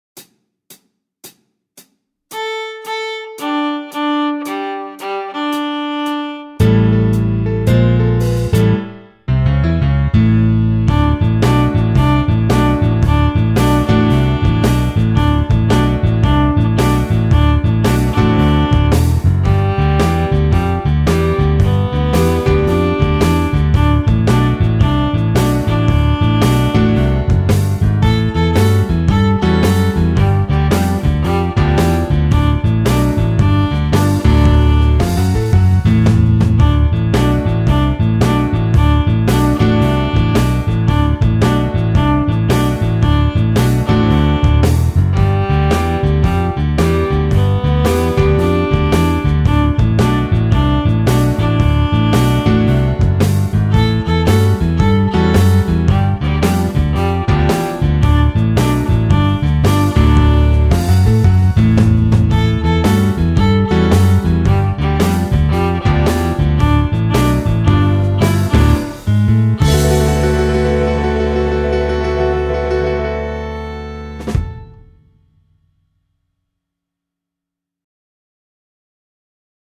De cada una de ellas hay un audio con el violín tocando y el piano y la batería acompañando y el segundo audio con la segunda voz, el piano y la batería.